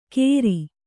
♪ kēri